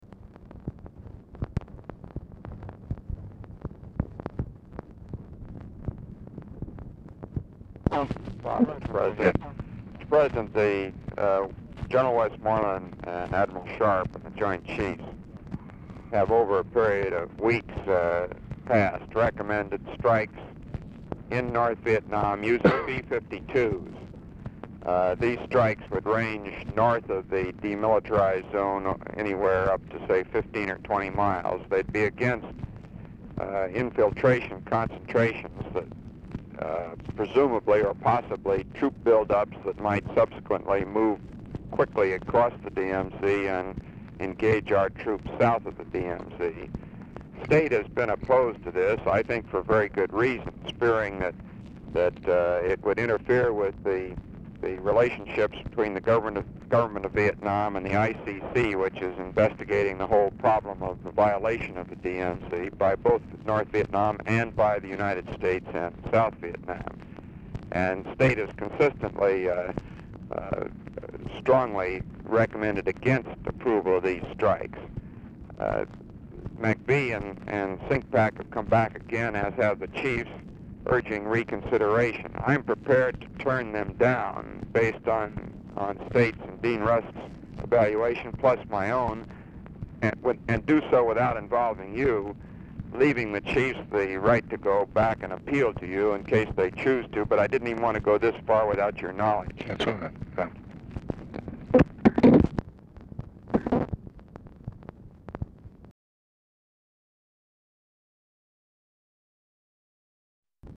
Telephone conversation # 10733, sound recording, LBJ and ROBERT MCNAMARA, 9/9/1966, 2:03PM | Discover LBJ
Format Dictation belt
Specific Item Type Telephone conversation Subject Defense Diplomacy Vietnam